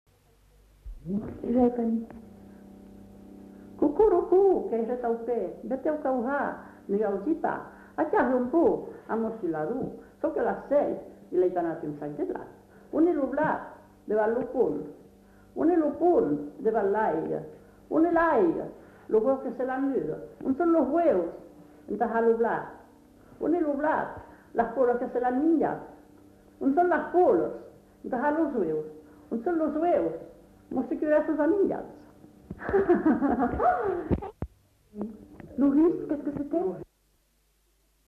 Lieu : [sans lieu] ; Landes
Genre : forme brève
Type de voix : voix de femme
Production du son : récité
Classification : mimologisme